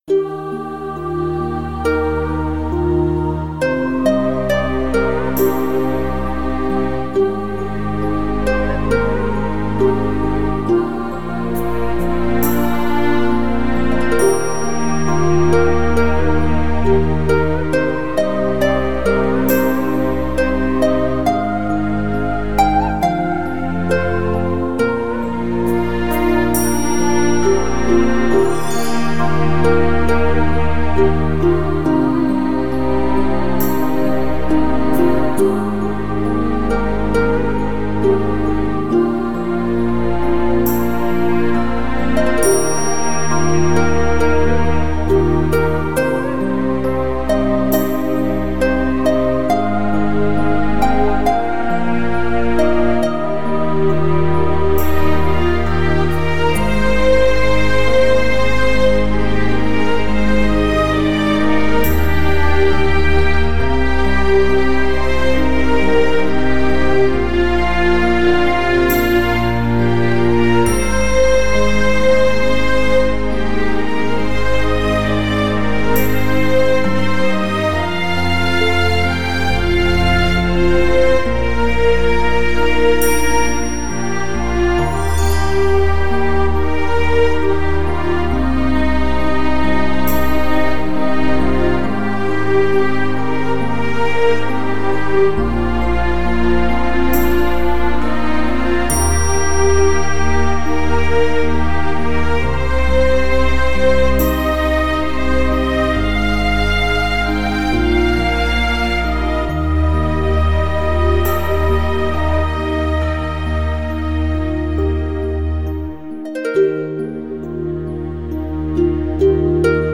[1/6/2010]【佛乐】永恒之地 激动社区，陪你一起慢慢变老！